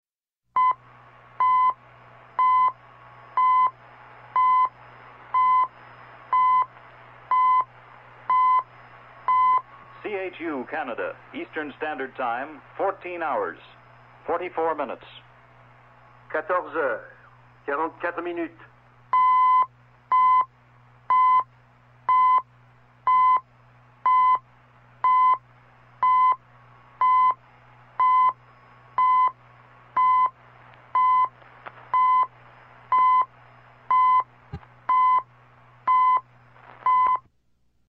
while in Philadelphia, PA, USA